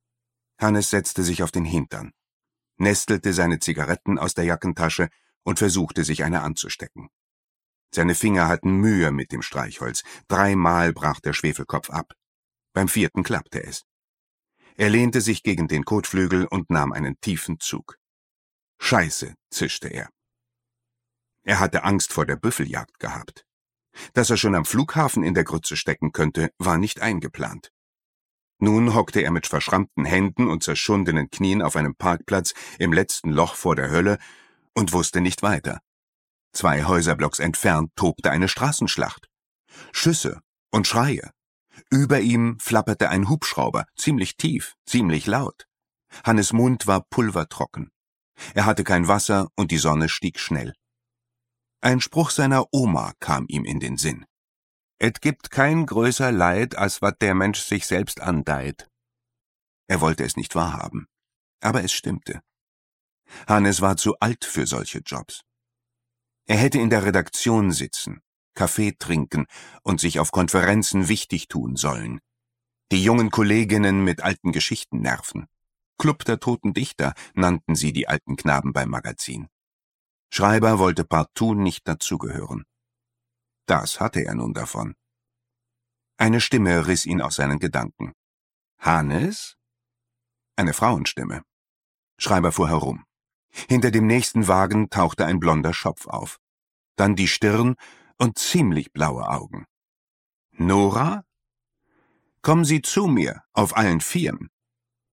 Lesung
Vier Krimis als Horbücher